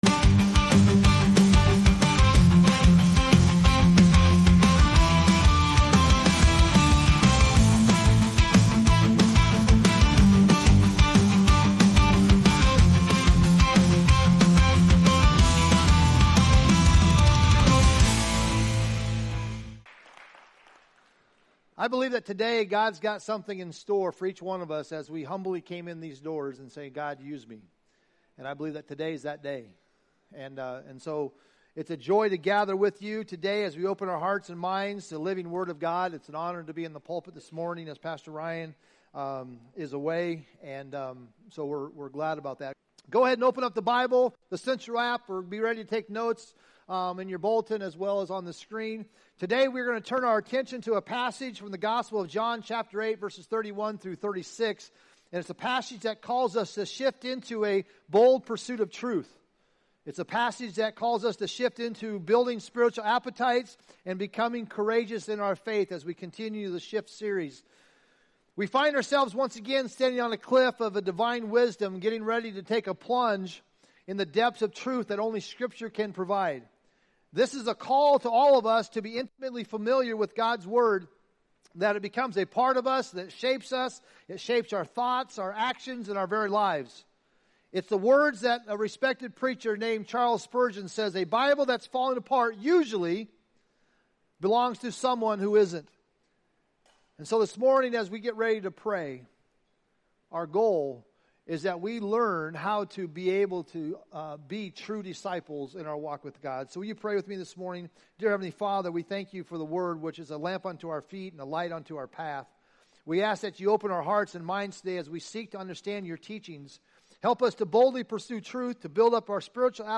Welcome to Central Church!